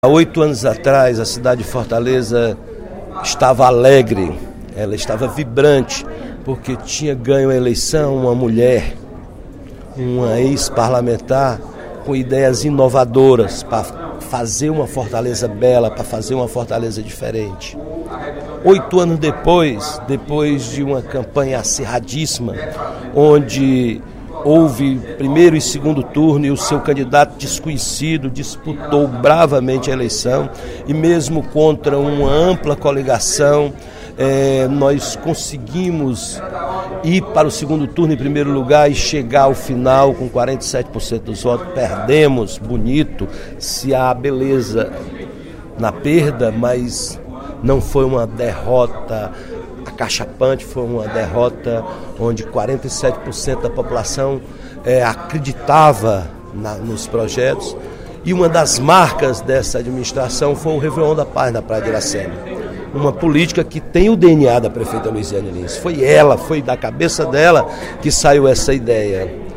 Durante pronunciamento, no primeiro expediente da sessão plenária desta quinta-feira (20/12), o deputado Roberto Mesquita (PV) lamentou a desistência da Prefeitura de realizar o Réveillon de 2012.